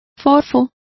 Complete with pronunciation of the translation of flabby.